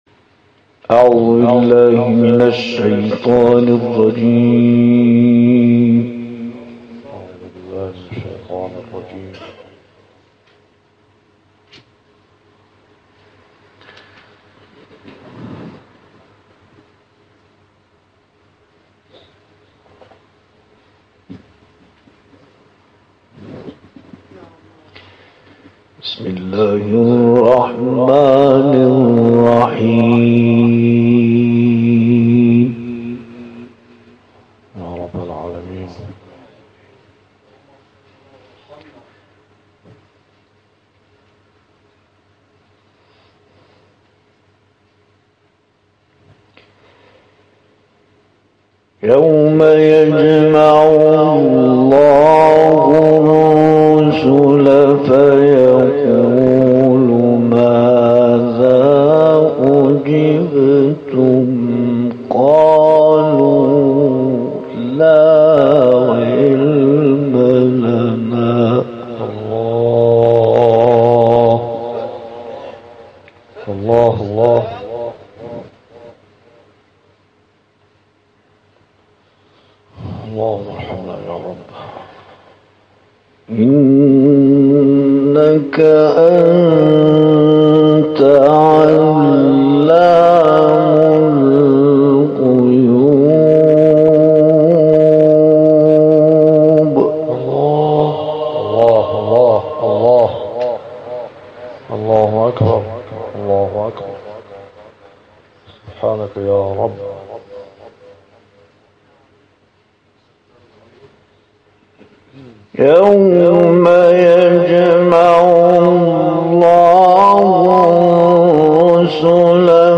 جدیدترین تلاوت